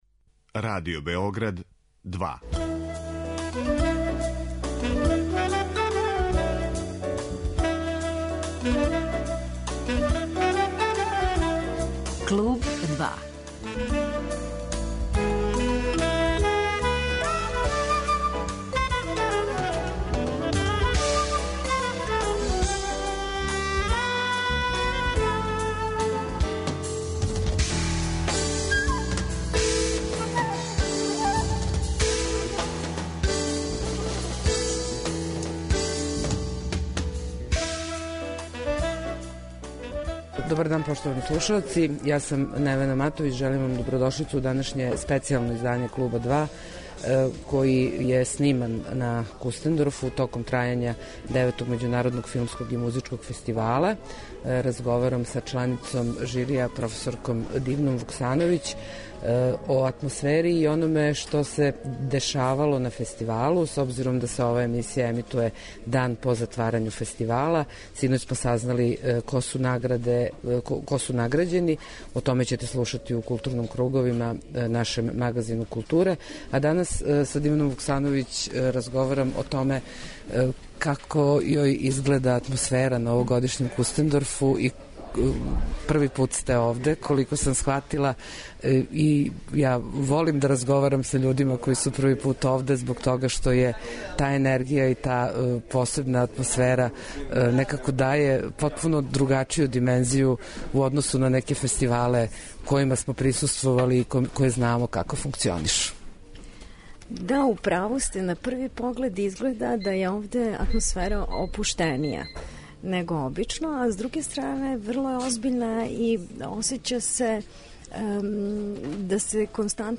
Данашње, специјално издање емисије 'Клуб 2' снимљено је на Мокрој Гори, где је синоћ зaвршен 9. Међународни филмски и музички фестивал - Кустендорф.
Разговор који ћете чути је снимљен, из чисто практичног разлога; у овом тренутку аутобус у коме се налазе акредитовани новинари је на путу ка Београду.